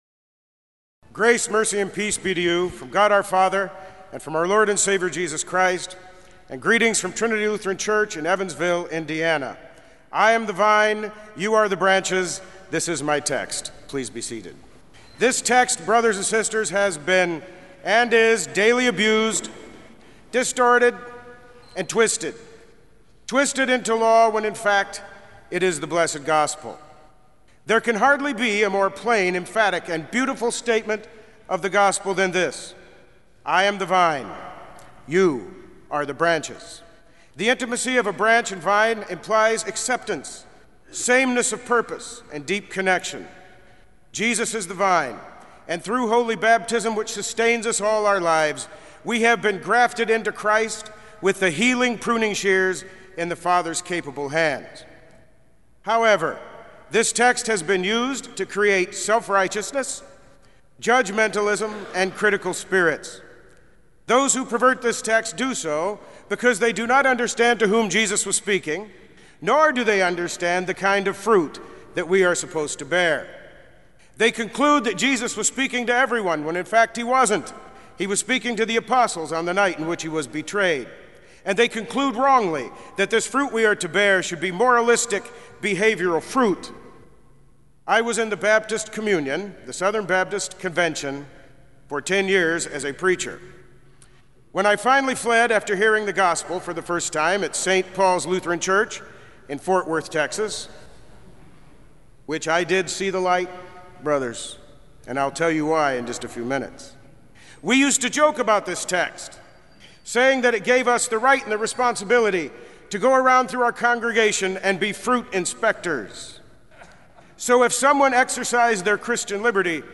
Kramer Chapel Sermon - May 16, 2006